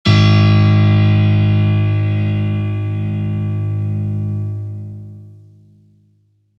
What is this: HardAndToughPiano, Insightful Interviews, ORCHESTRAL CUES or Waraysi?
HardAndToughPiano